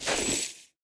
PixelPerfectionCE/assets/minecraft/sounds/item/armor/equip_chain3.ogg at mc116
equip_chain3.ogg